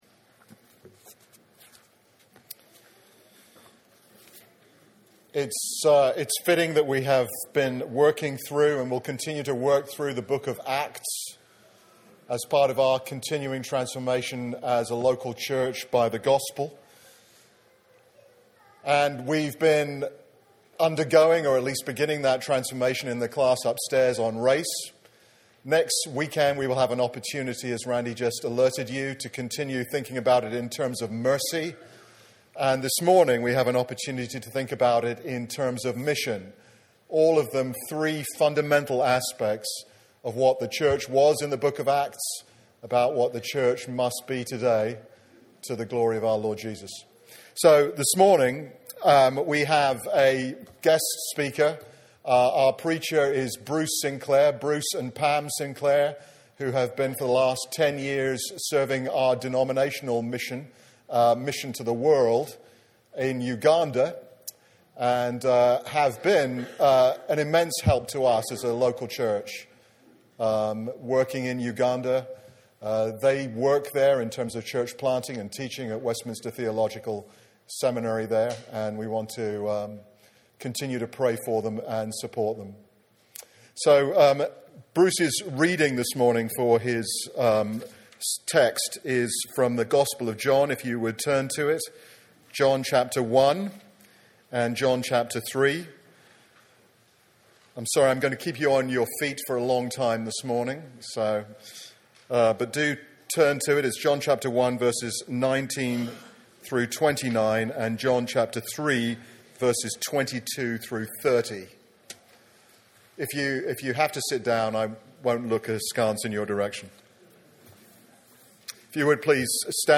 sermon-audio-4.26.15.mp3